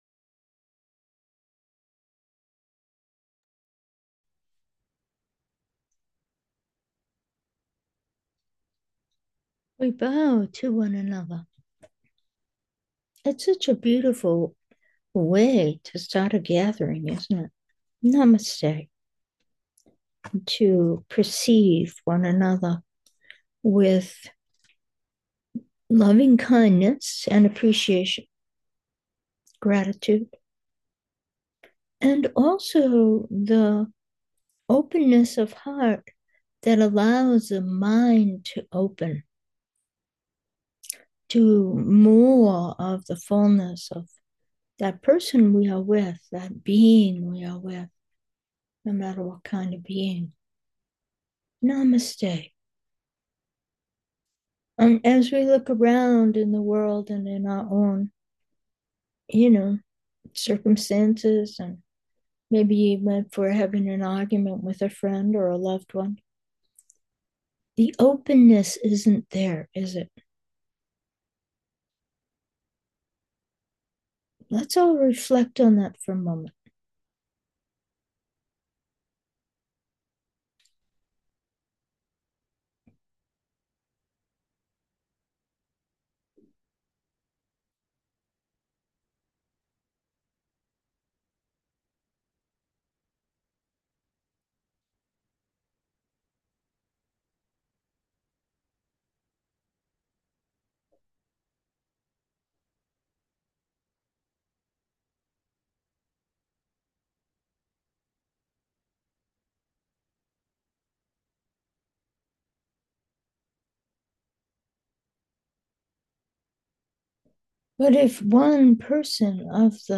Meditation: open 1